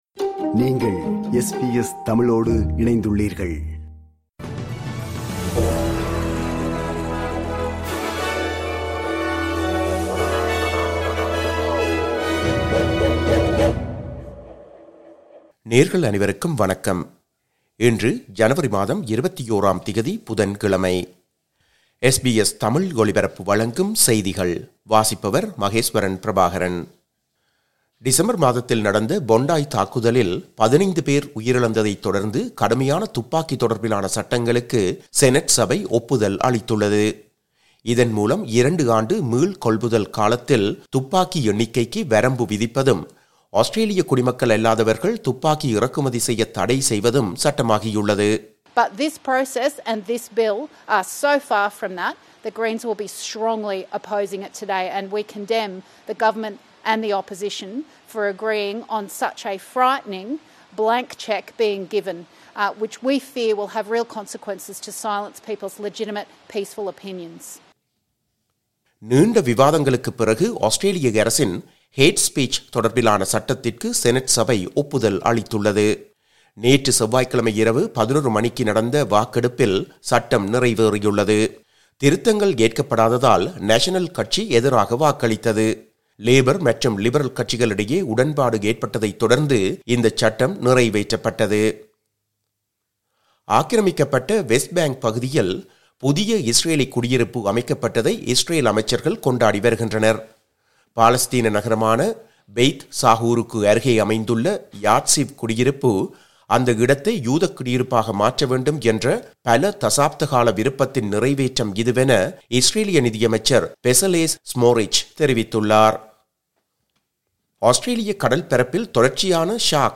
இன்றைய செய்திகள்: 21 ஜனவரி 2026, புதன்கிழமை.
SBS தமிழ் ஒலிபரப்பின் இன்றைய (புதன்கிழமை 21/01/2026) செய்திகள்.